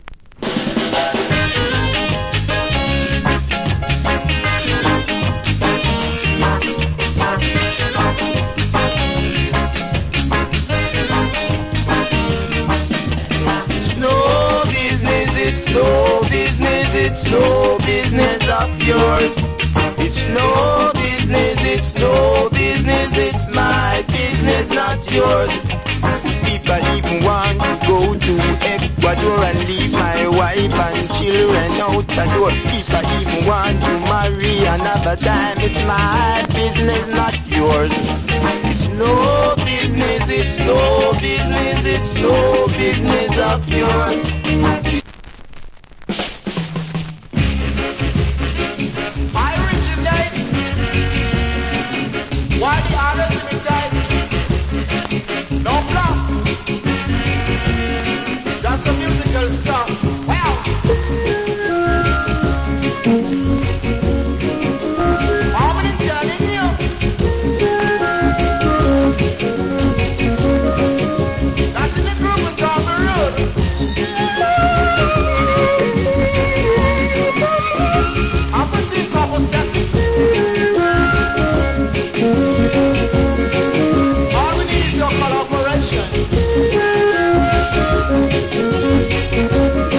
Good Early Reggae Vocal.